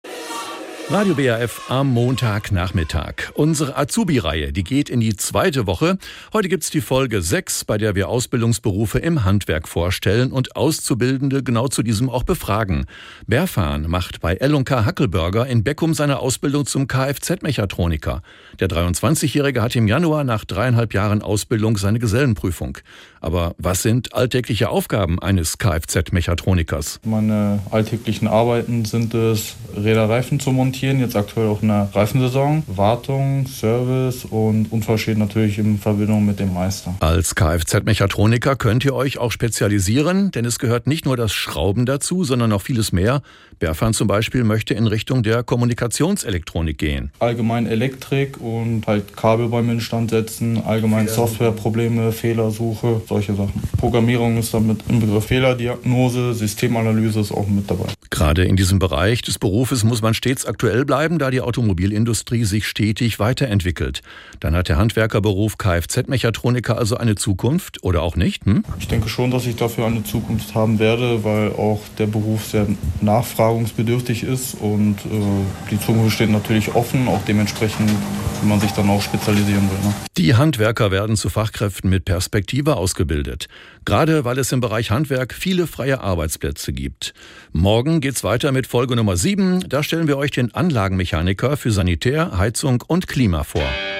Zusammen mit Radio WAF lassen wir die Azubis zu Wort kommen: Zwei Wochen lang erzählen Auszubildende im Programm des Lokalsenders, warum die Ausbildung im Handwerk für sie genau der richtige Start ins Berufsleben ist.
Mit freundlicher Genehmigung von Radio WAF können wir die Mitschnitte der Beiträge hier nach der Ausstrahlung zum Nachhören veröffentlichen.